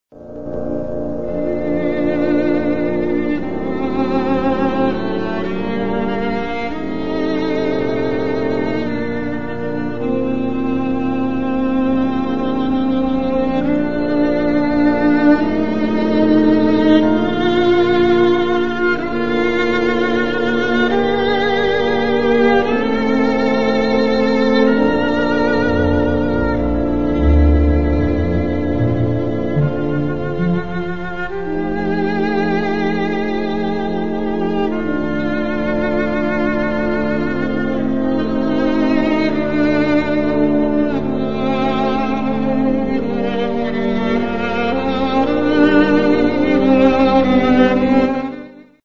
Un sens inné du phrasé, une technique à faire pâlir les virtuoses eux-même.
Tout la panoplie des coups d'archets est présente dans ce concerto, ricochets, staccato, martelés etc...
le tempo, pas trop rapide donne à l'œuvre un caractère encore plus profond s'il en était besoin.
impressionnante de douceur dans les pianissimi
Pièces pour violon et orchestre